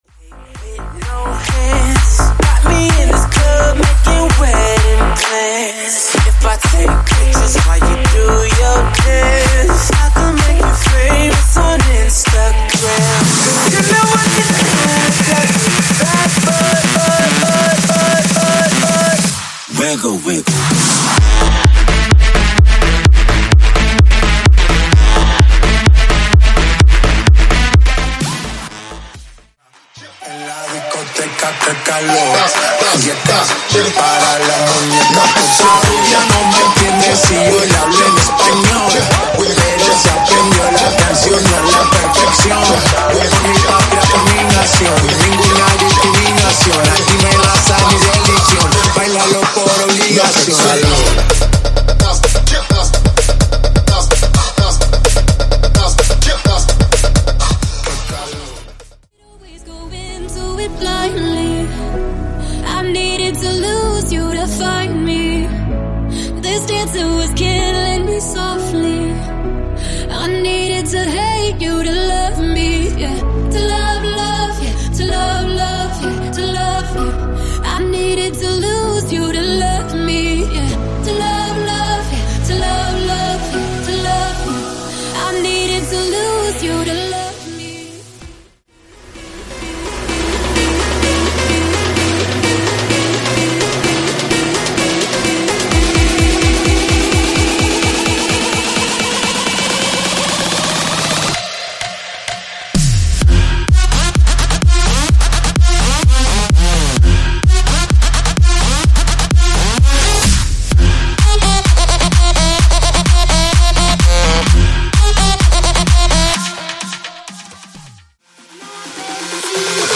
80s New Wave Redrum) 128 Bpm